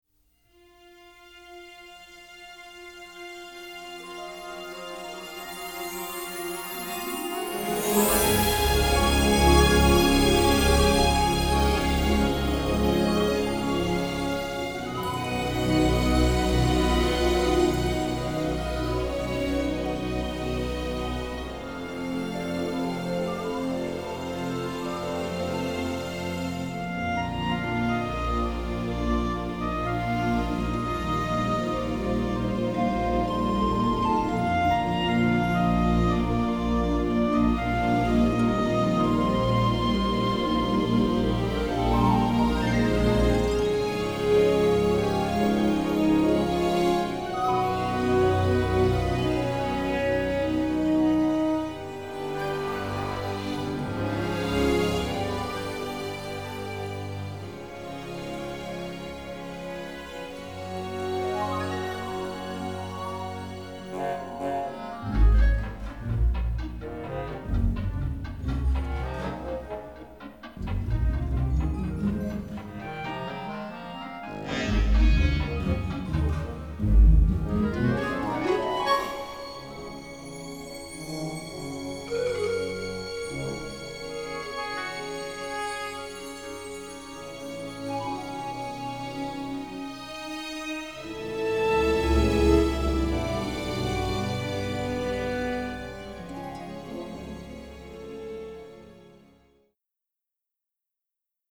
generous string section plus brass ensemble